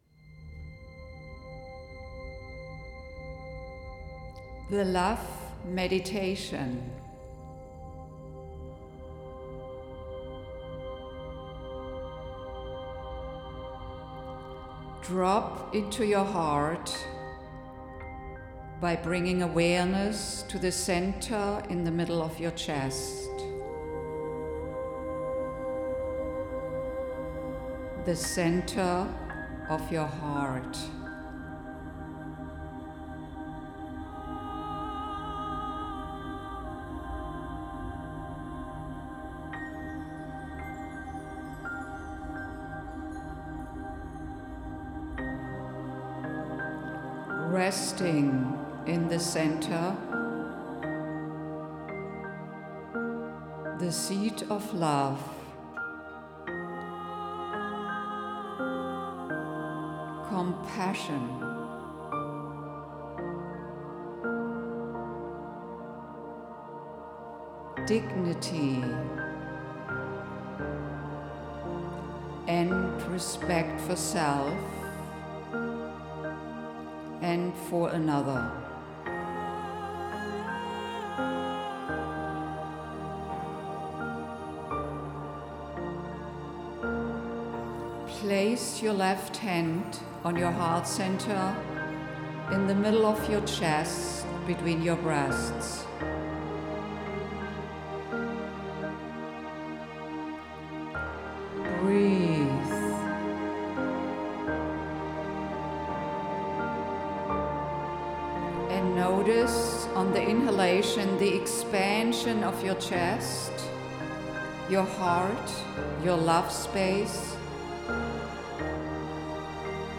Love Meditation
Music: Barry Goldstein